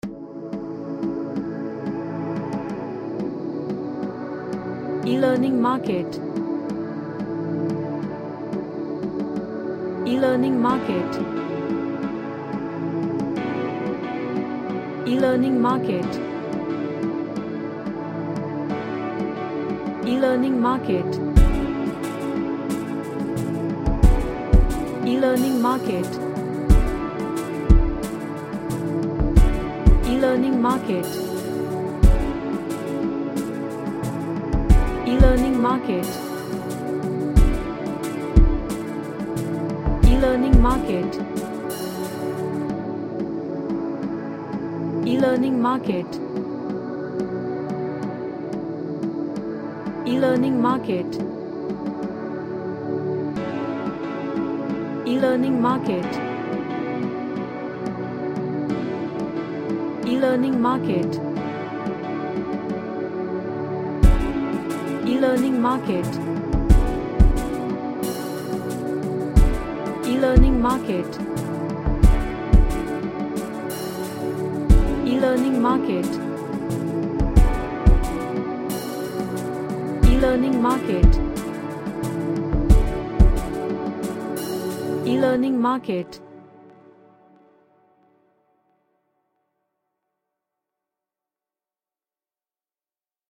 A Ethnic track with ambient.
Happy